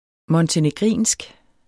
montenegrinsk adjektiv Bøjning -, -e Udtale [ mʌntəneˈgʁiˀnsg ] Betydninger 1. fra Montenegro; vedr.